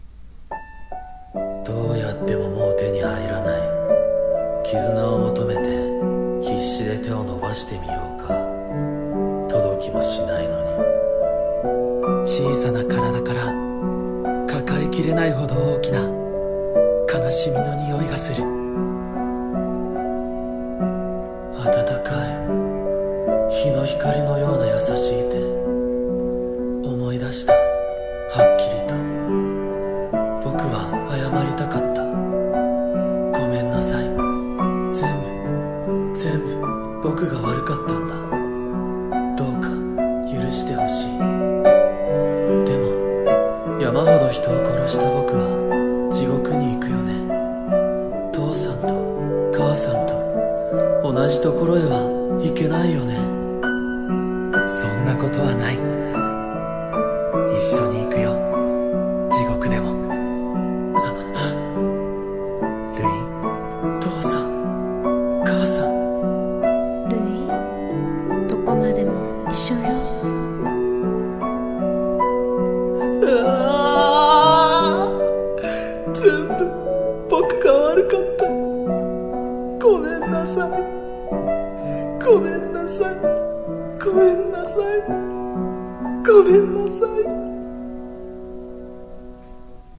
鬼滅の刃 累の最期【声劇台本】